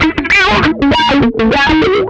MANIC WAH 15.wav